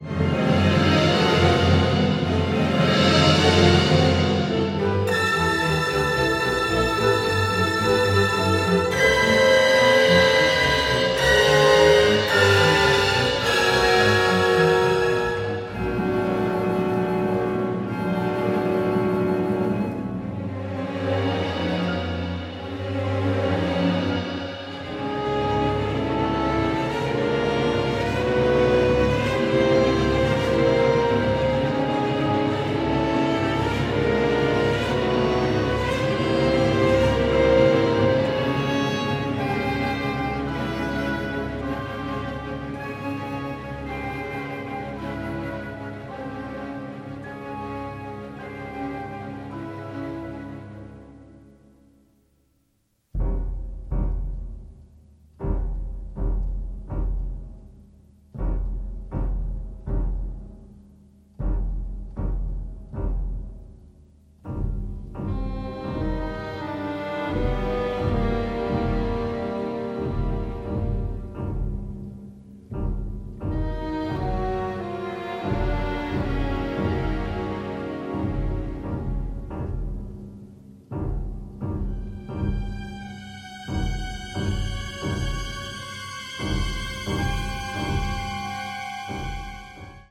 the score is operatic in its power